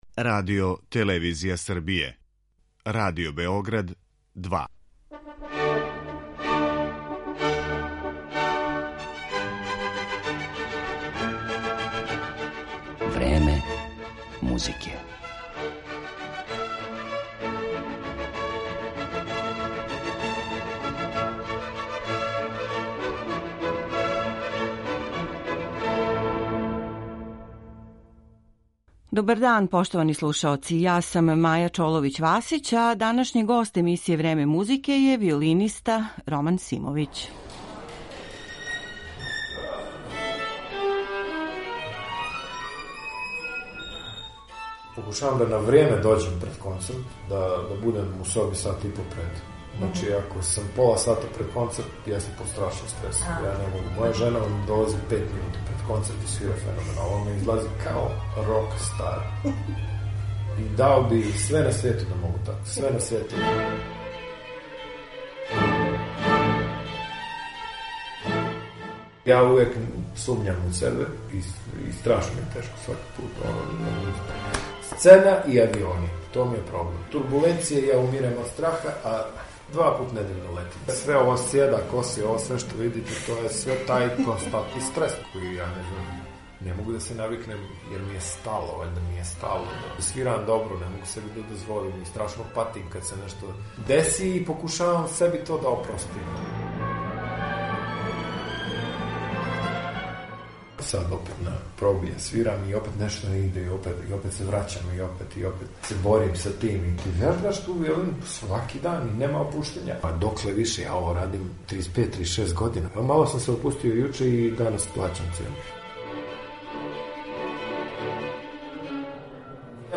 Muzički deo emisije čine snimci dela Paganinija, Prokofjeva, Debisija i drugih.